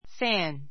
fǽn ふァ ン